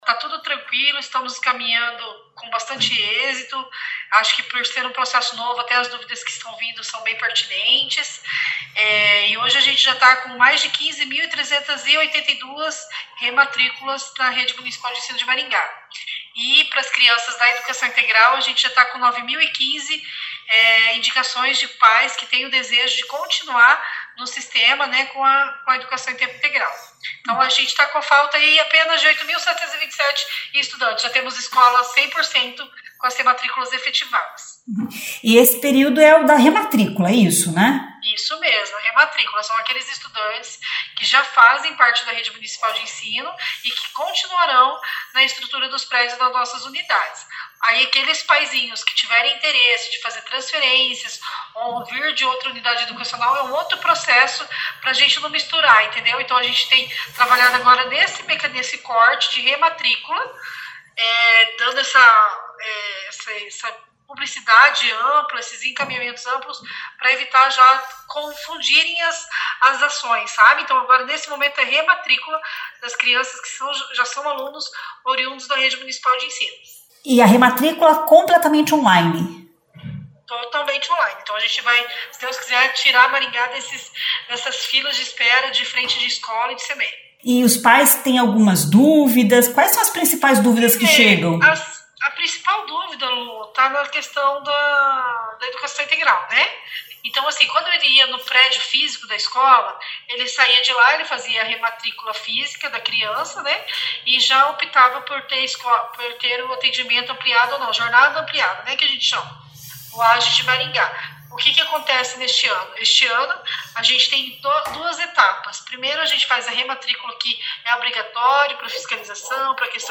A secretária Municipal de Educação, Adriana Palmieri, explica que a principal dúvida é em relação à educação integral.